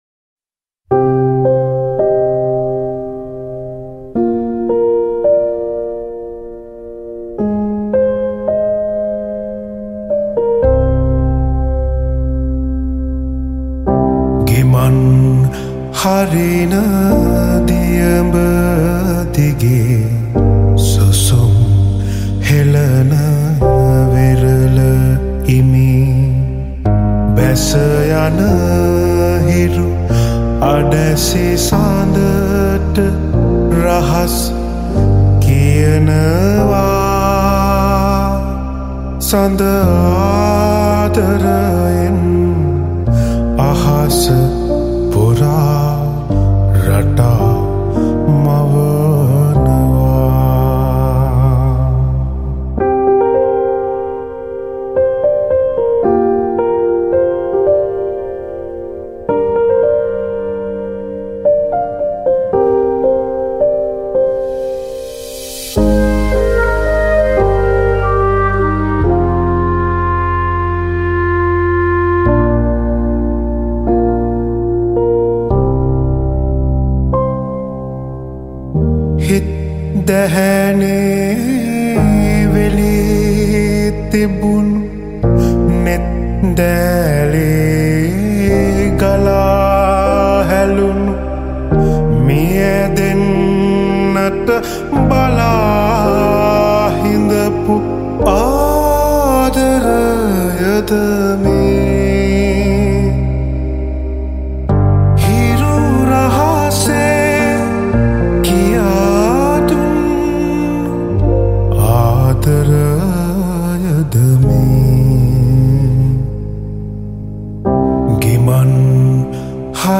high quality remix